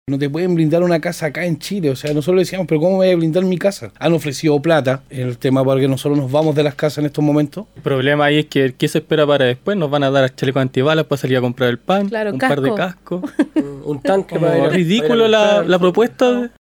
La semana pasada terminaron detenidos por protestar y hoy en entrevista con Radio Bío Bío un grupo de vecinos de Los Jardines de San Joaquín reiteró su rechazo a la iniciativa del Gobierno de blindar sus viviendas.